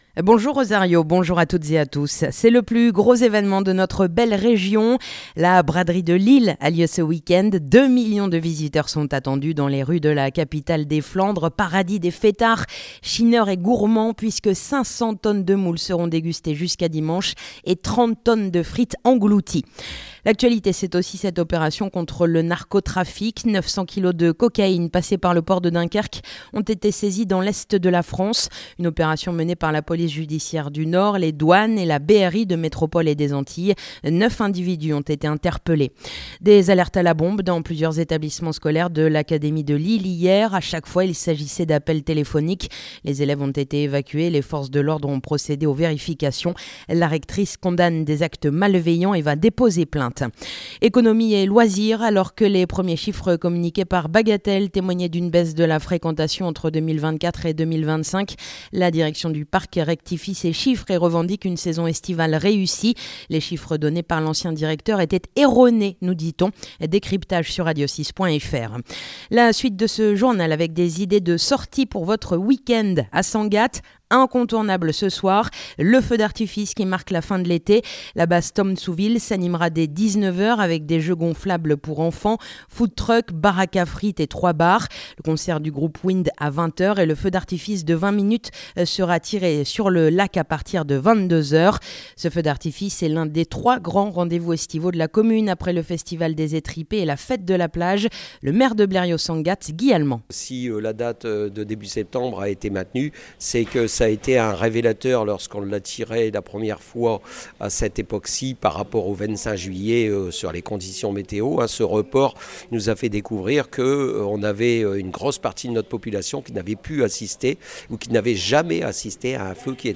Le journal du samedi 6 septembre